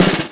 Snares
Roll (15).wav